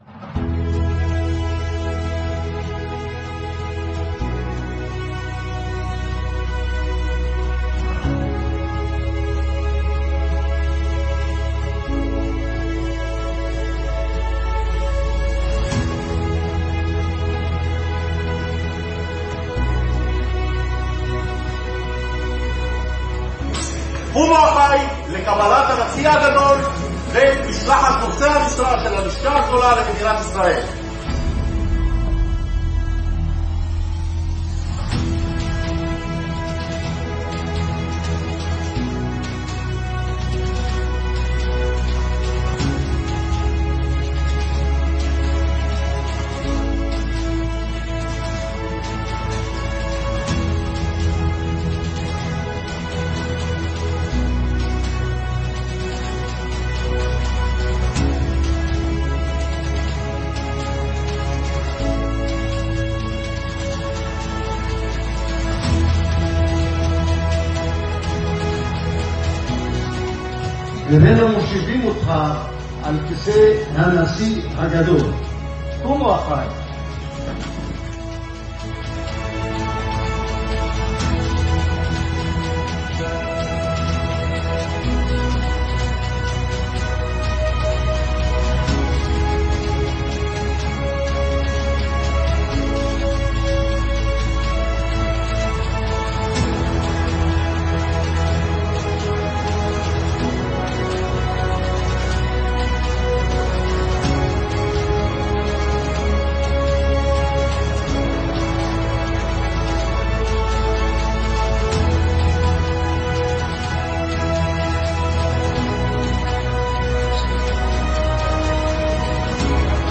תיעוד מטקס של הבניה החופשית